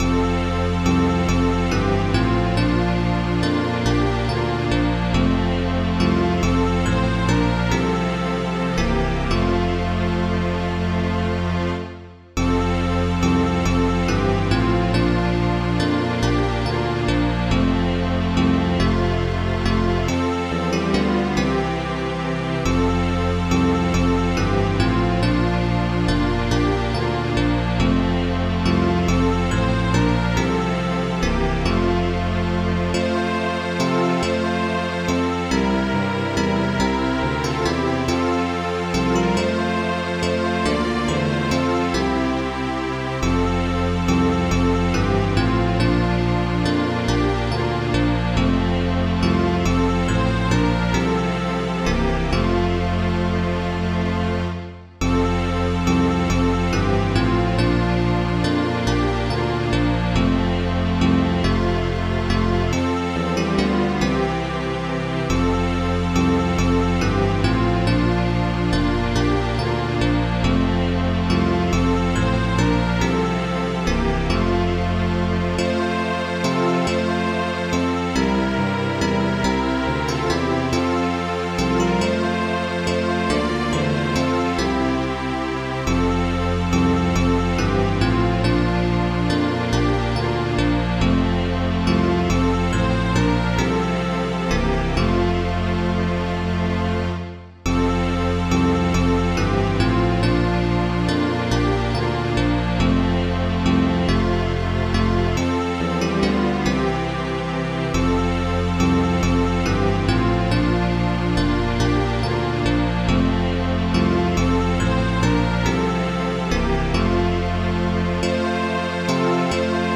An inspiring blend of the message with good instrument.